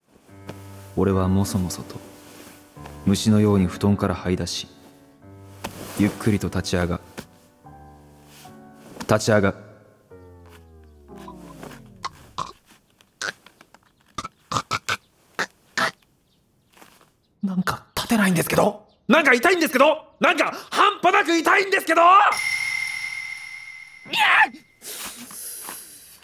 ドラマCD第3弾は、「転落人生」「逆転人生」「不屈」「腰痛」の4話を収録したDISC1とフリートークを収録したDISC2の2枚組！